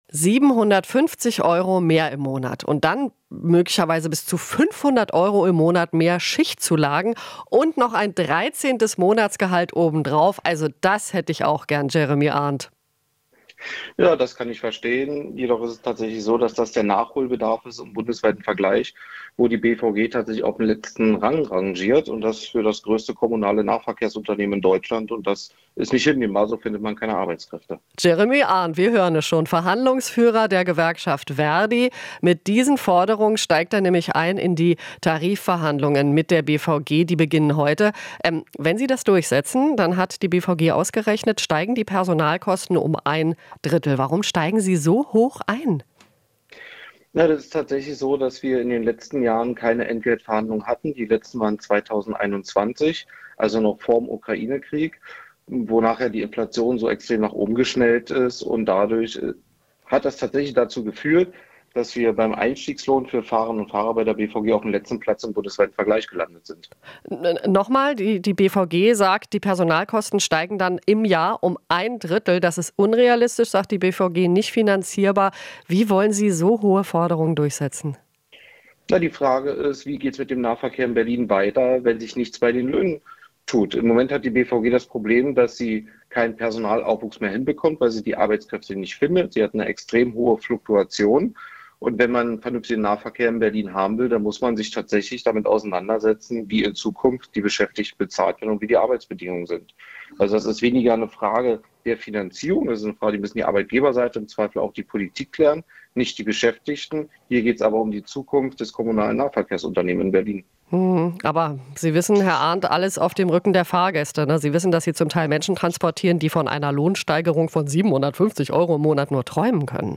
Interview - BVG-Tarifverhandlungen: Verdi droht mit Streiks